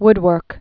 (wdwûrk)